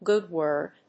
音節gòod wórd